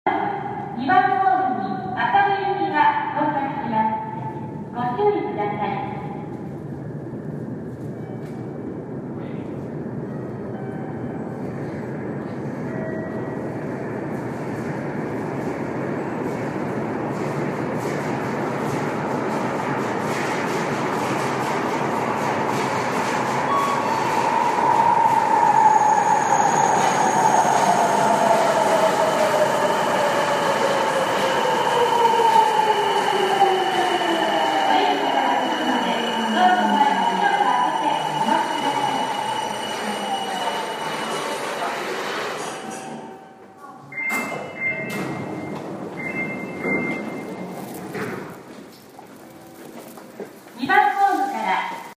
列车内部
描述：在伦敦地铁区间线列车内进行现场录音，列车从一个车站出发，穿过隧道，到达另一个平台。包括列车上的广播和旅程开始/结束时的车门关闭/打开
Tag: 现场记录 伦敦 伦敦的地下管火车